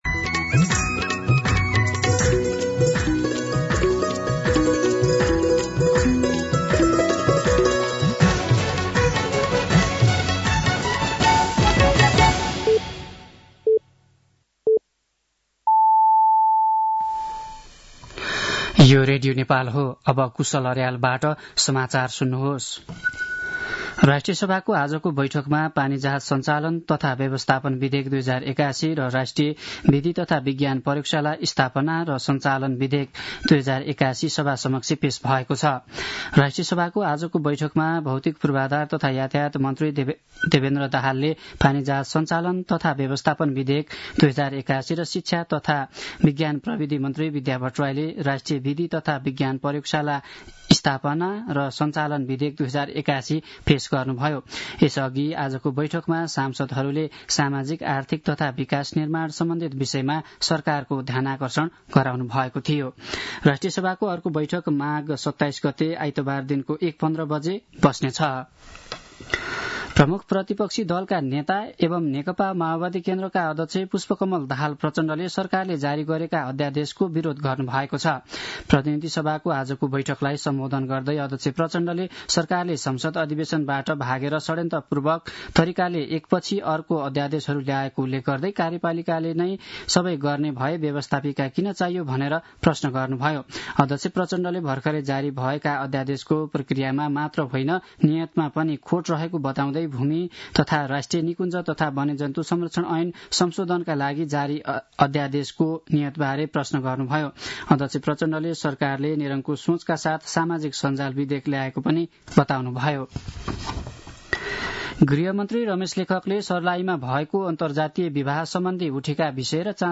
साँझ ५ बजेको नेपाली समाचार : २५ माघ , २०८१
5-pm-nepali-news-10-24.mp3